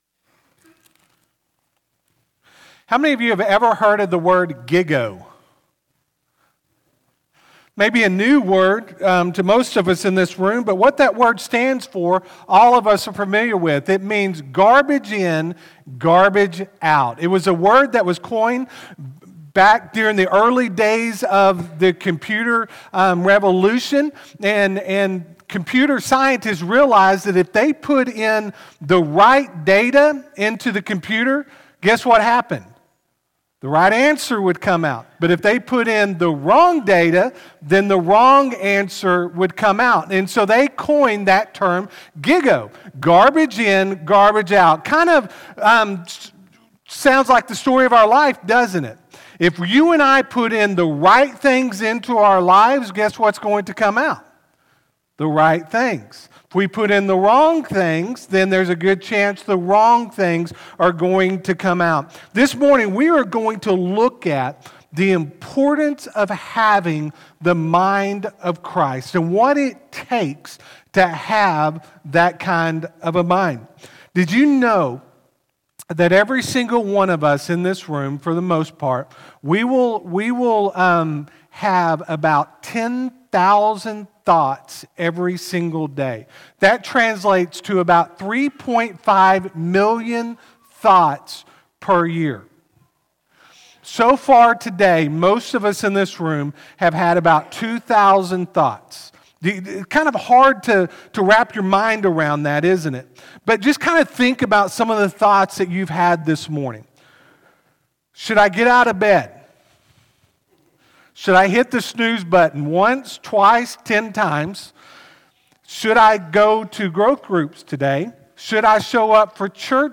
Friendship Baptist Church SERMONS